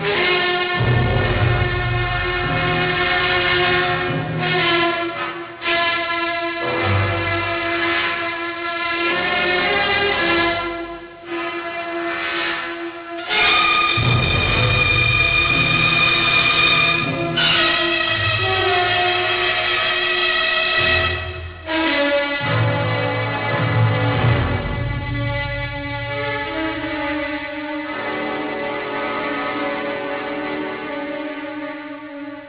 una folta e possente orchestra